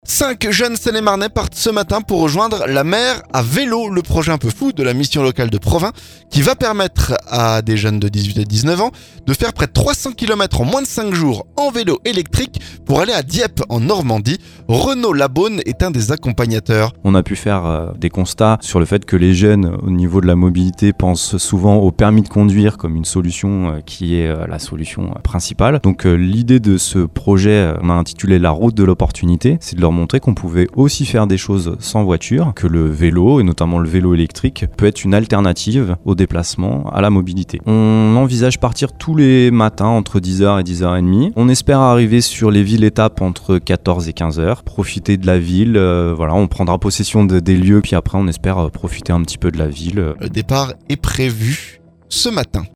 un des accompagnateurs, temoigne.